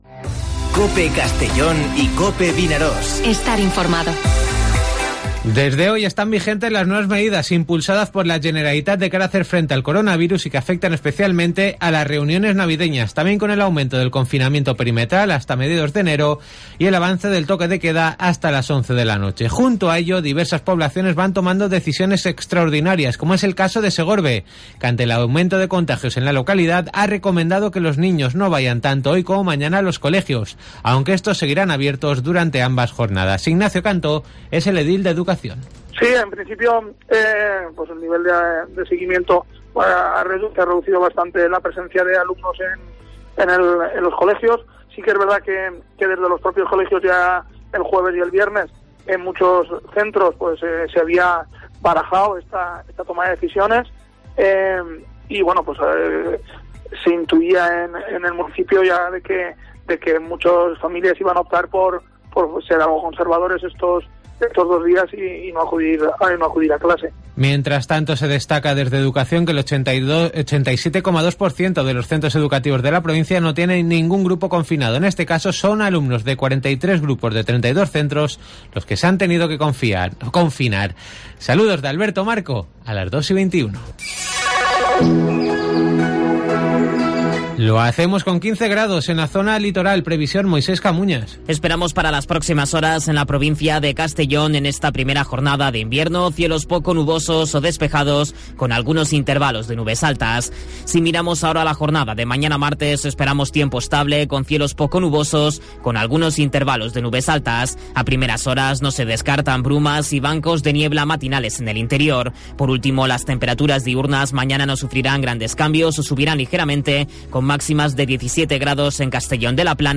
Informativo Mediodía COPE en Castellón (21/12/2020)